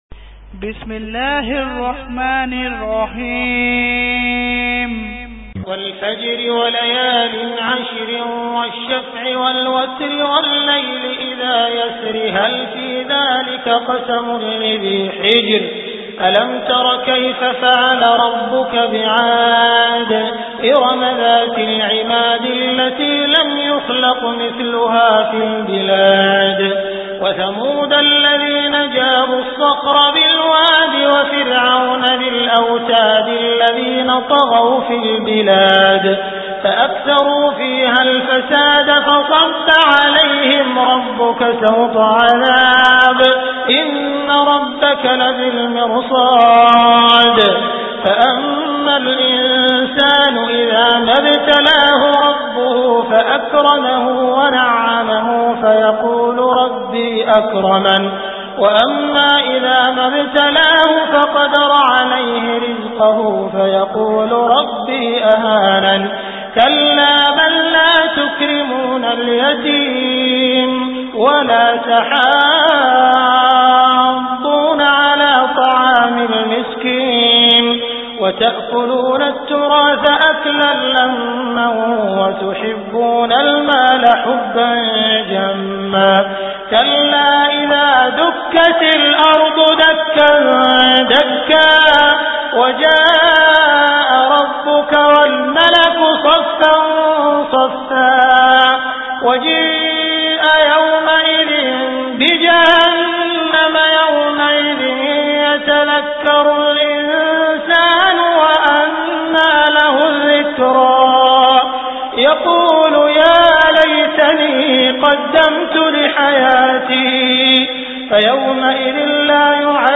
Surah Al Fajr Beautiful Recitation MP3 Download By Abdul Rahman Al Sudais in best audio quality.